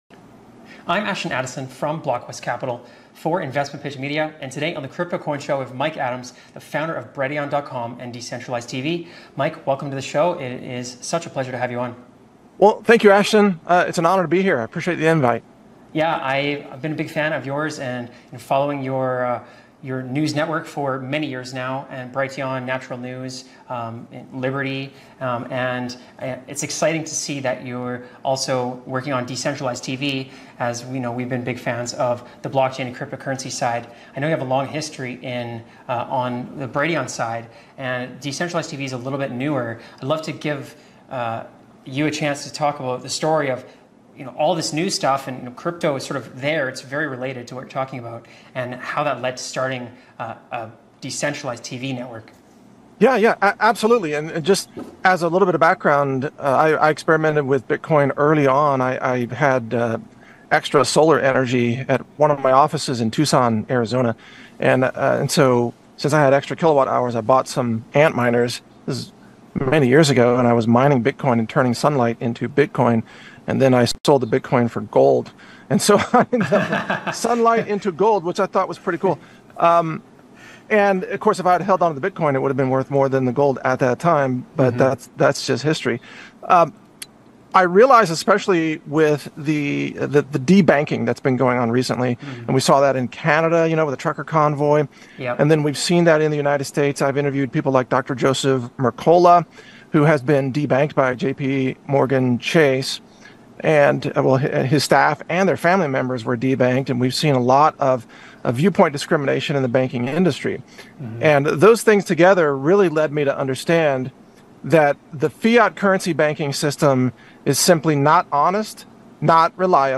Death of US Dollar to cause Bitcoin All Time High? w/ Mike Adams of Brighteon | BC Interviews